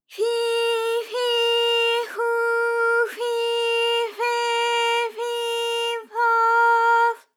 ALYS-DB-001-JPN - First Japanese UTAU vocal library of ALYS.
fi_fi_fu_fi_fe_fi_fo_f.wav